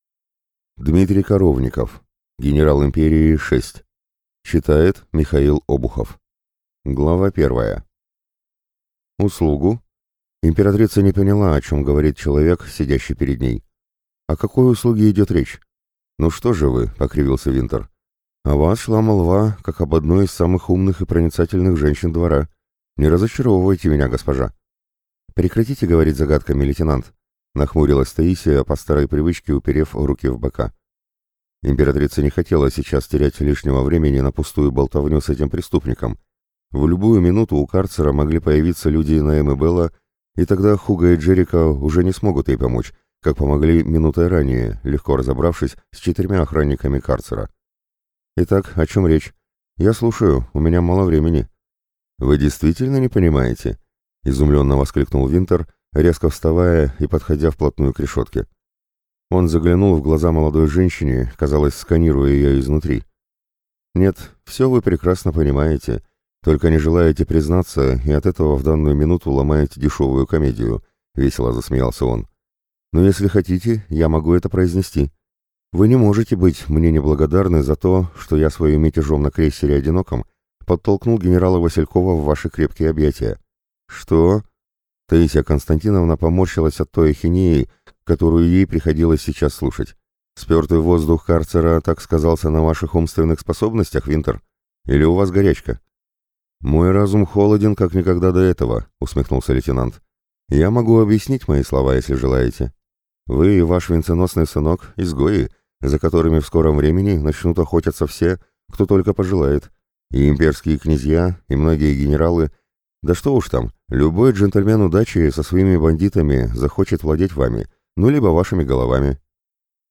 Aудиокнига Генерал Империи – 6